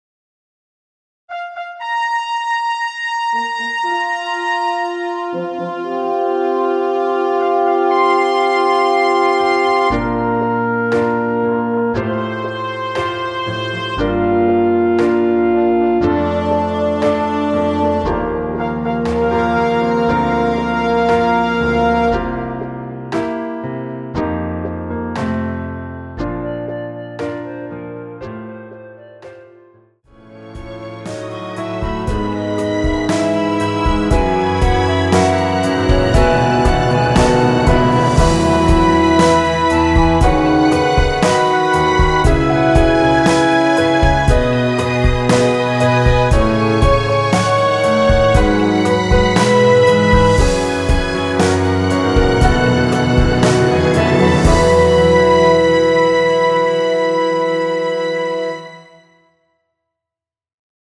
엔딩이 너무 길어 라이브에 사용하시기 좋게 짧게 편곡 하였습니다.(원키 미리듣기 참조)
원키에서(+5)올린 멜로디 포함된 MR입니다.
Bb
앞부분30초, 뒷부분30초씩 편집해서 올려 드리고 있습니다.
중간에 음이 끈어지고 다시 나오는 이유는